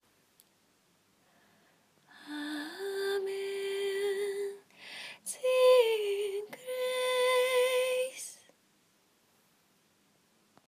歌の中でウィスパーボイス使って表現すると　↑　参考例
whisper-voice.m4a